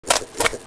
Heroes3_-_Infernal_Troglodyte_-_MoveSound.ogg